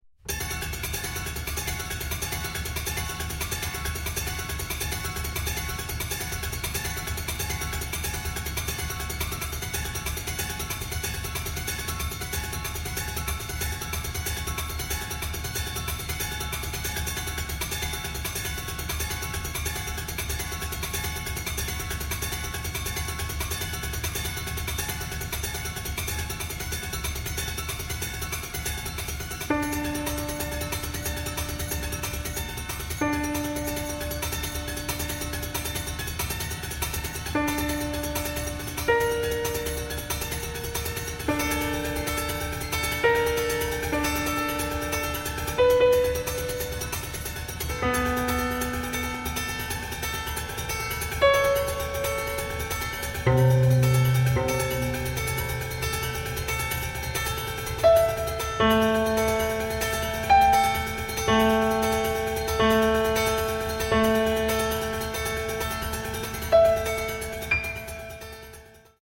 for solo piano, transducers, and field recordings